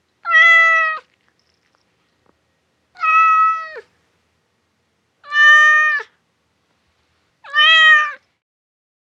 Cat-meow-sound-effect.mp3